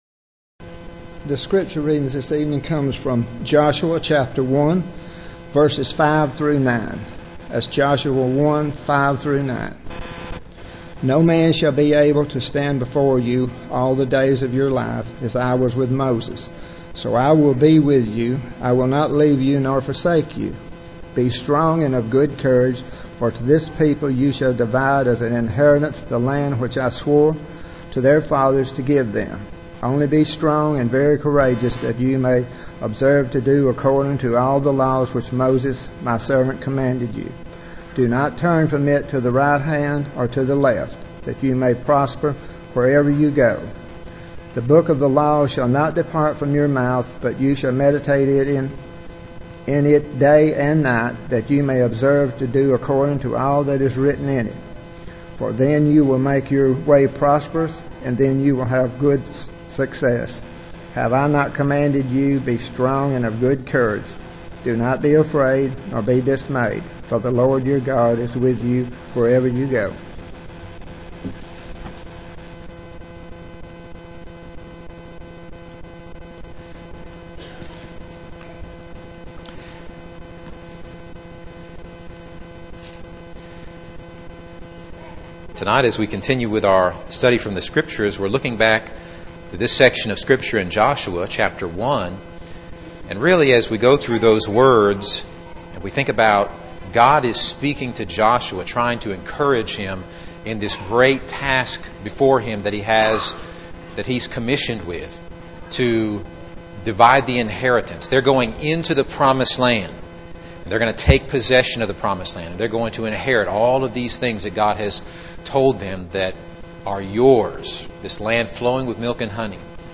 Eastside Sermons Service Type: Sunday Evening « Christian Parenting